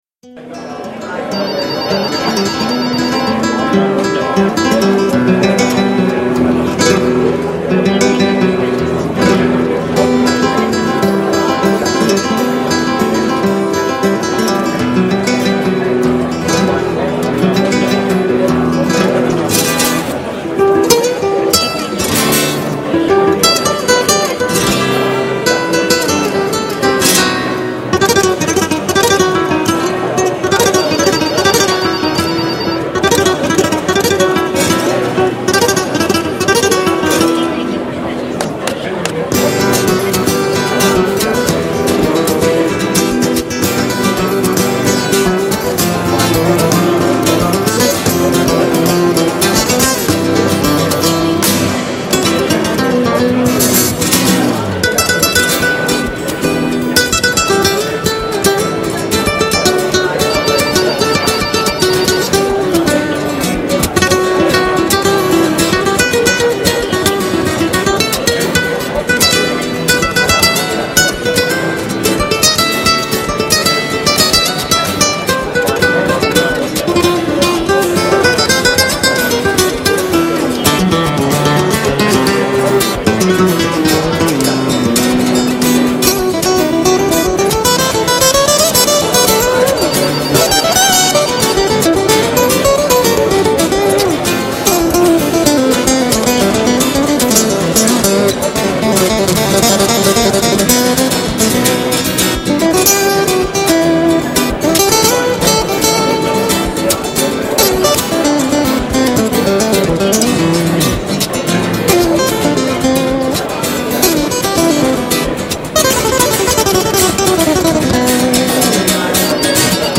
Hay que visionar todas las fotograf�as con esa maravillosa m�sica flamenca pulsando el logo y volver a la ventana de las im�genes